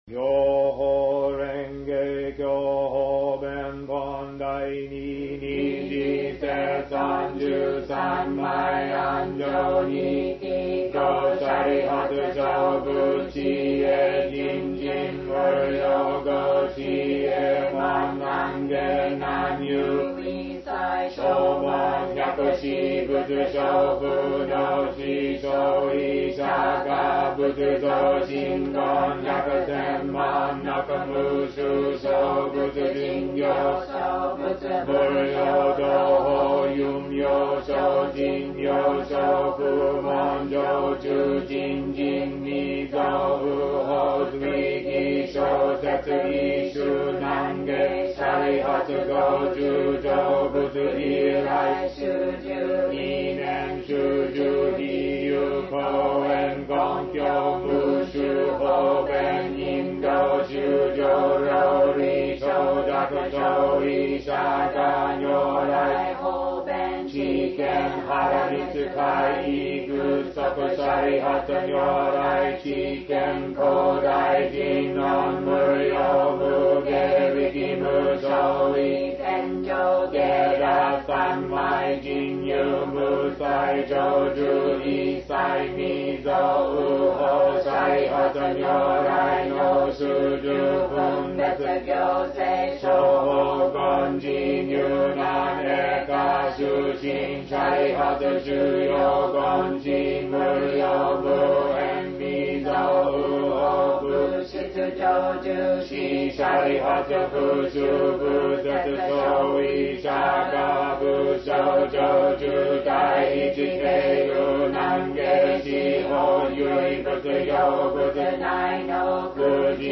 Das Gongyo, sehr langsam rezitiert, zum Anhören und Lernen.